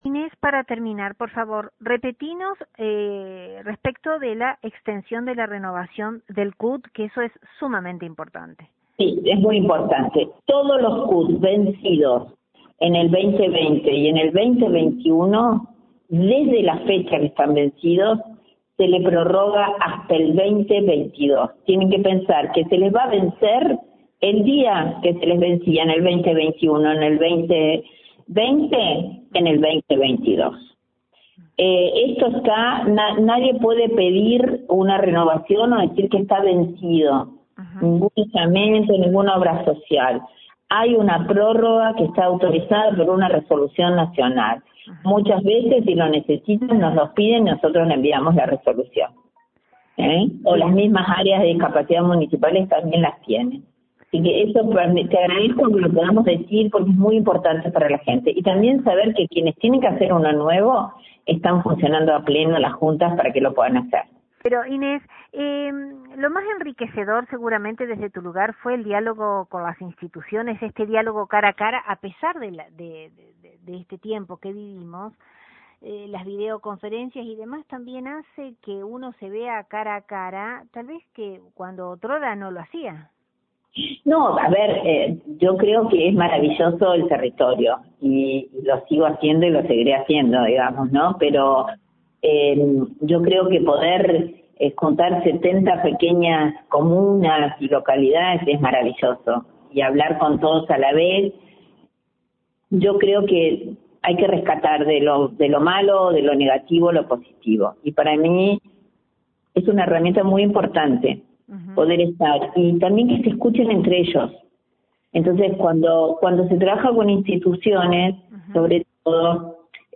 Desde LT39 NOTICIAS, dialogamos con su Directora Inés Artussi, sobre este importante organismo gubernamental, tan caro a una población determinada de nuestra sociedad; quien prima facie, destacó la prórroga de los CUD, certificados únicos de discapacidad, que extiende la provincia.